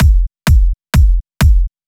VTDS2 Song Kit 08 Male Out Of My Mind Kick.wav